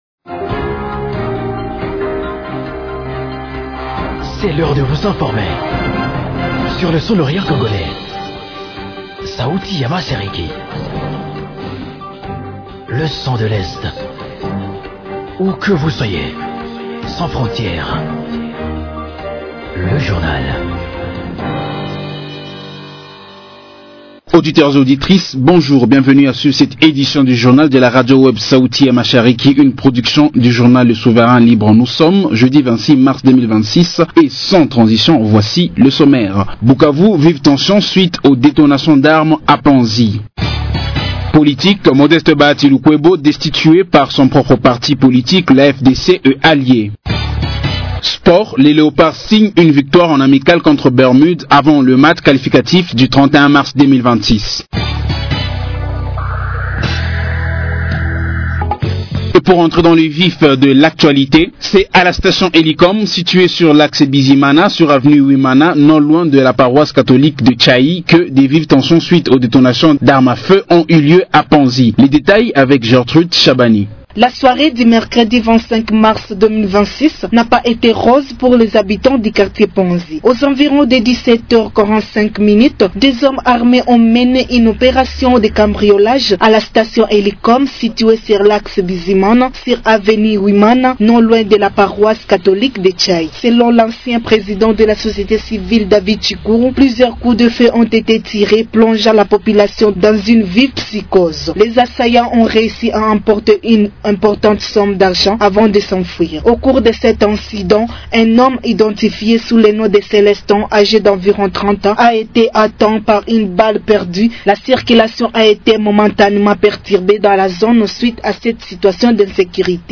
Journal du 26.03.2026